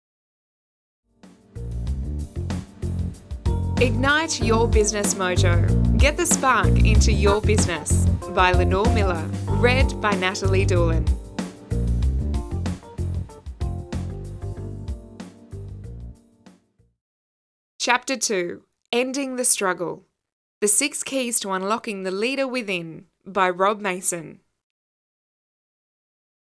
Female
English (Australian)
Teenager (13-17), Adult (30-50)
Audiobooks
23899DemoAudiobookSample.mp3